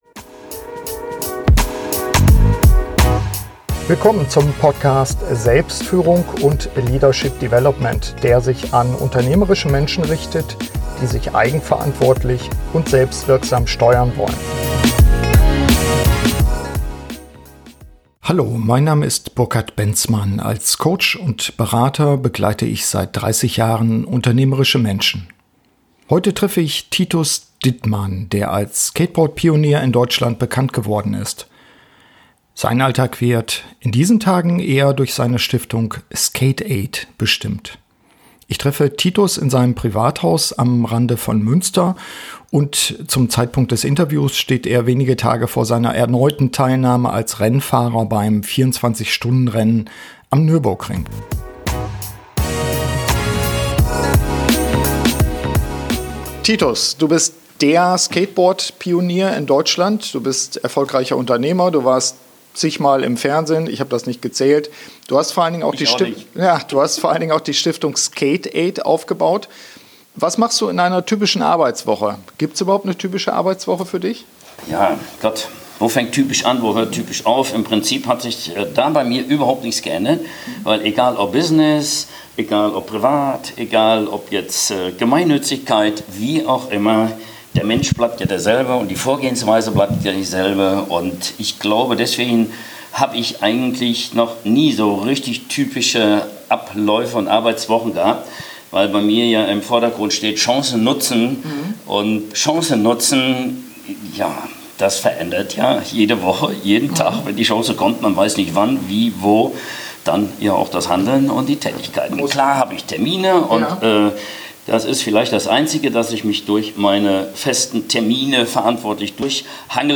SF55 Handeln wie ein Unternehmer - mein Interview mit Titus Dittmann ~ Selbstführung und Leadership Development Podcast